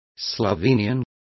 Complete with pronunciation of the translation of slovenian.